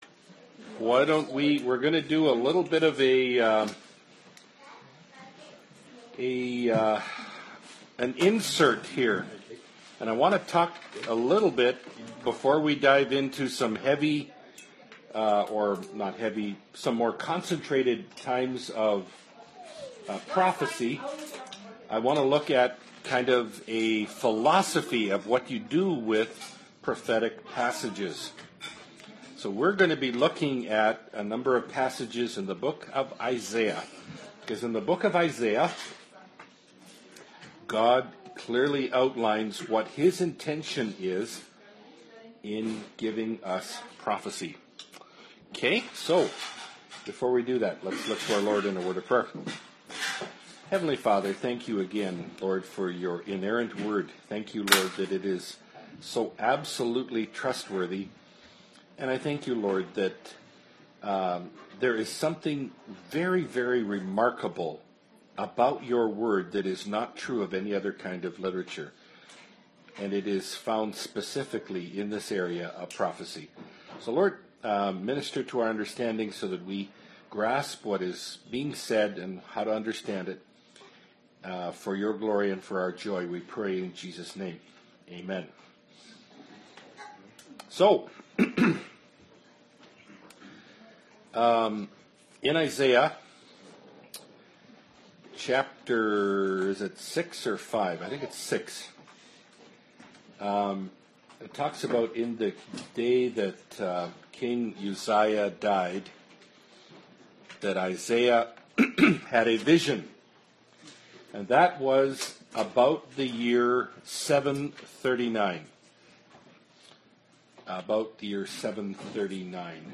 Bible Study – Daniel 5b (Sidetrack into Isaiah) (2017)
Bible-Study-Daniel-5b-Sidetrack-into-Isaiah-2017.mp3